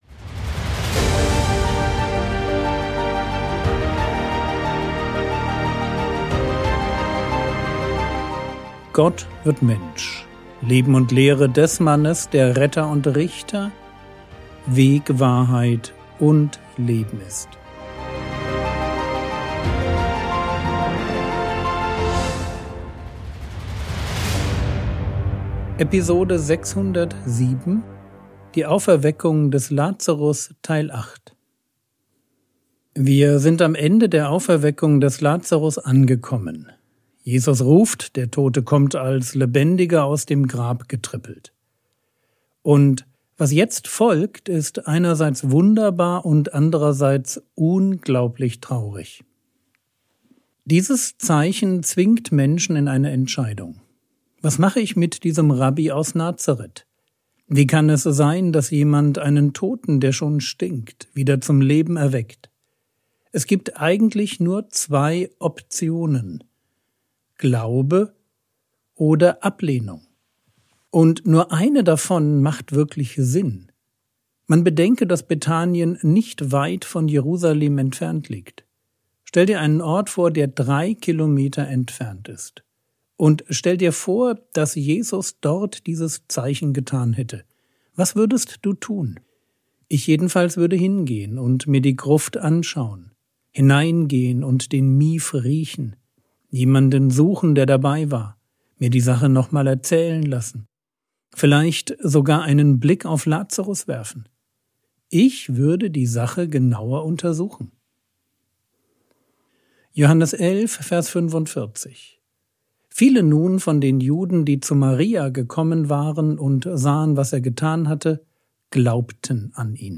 Episode 607 | Jesu Leben und Lehre ~ Frogwords Mini-Predigt Podcast